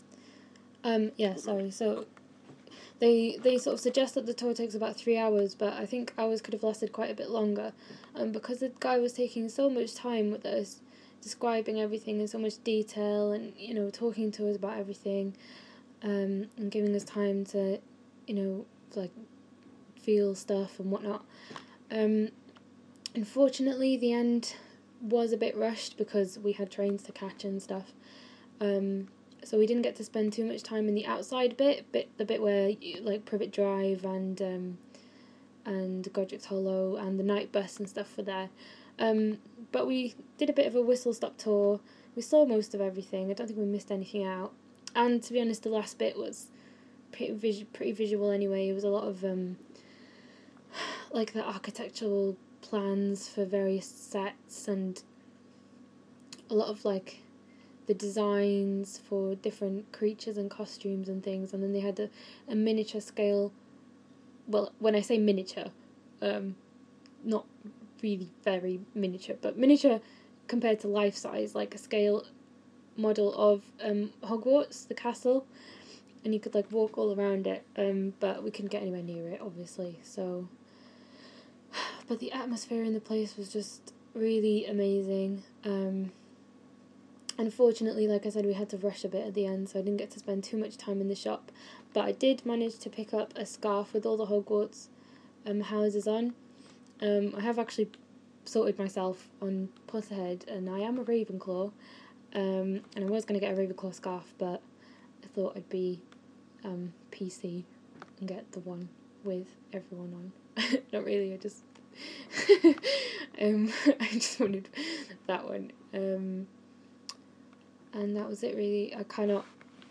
Harry Potter Warner Bros Studios audio described part three